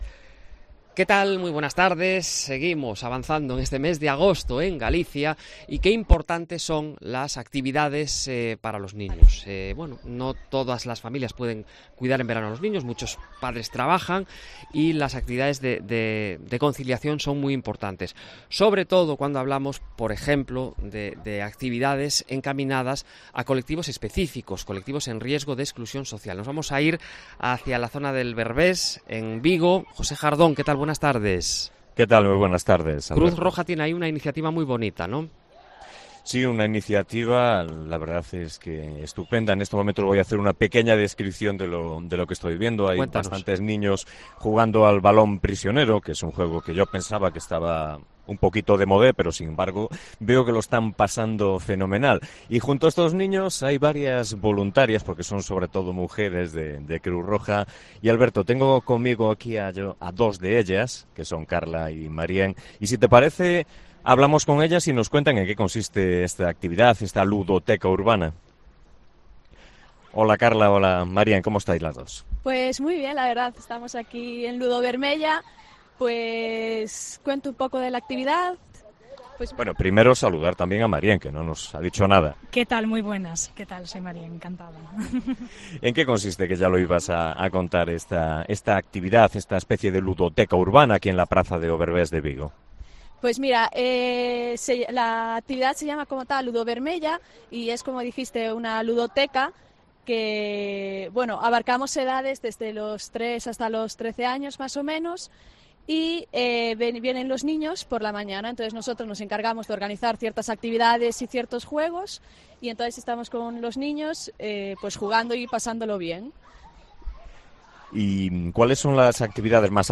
AUDIO: Hablamos con dos voluntarias que juegan con niños en riesgo de exclusión social